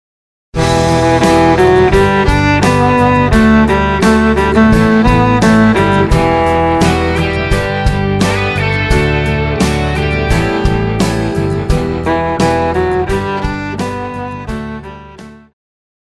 流行
中提琴
乐团
演奏曲
朋克
仅伴奏
没有主奏
有节拍器